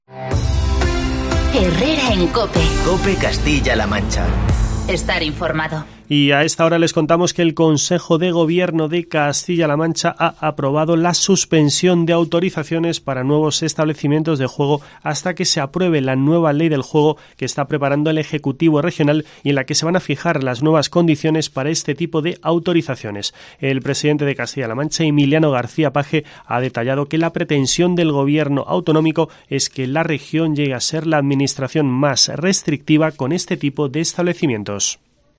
Así lo ha avanzado el presidente, Emiliano García-Page
Así lo ha avanzado hoy el presidente del Ejecutivo regional en la rueda de prensa que ha ofrecido a los medios de comunicación en un receso de la reunión del Consejo de Gobierno itinerante que se ha celebrado en Campo de Criptana.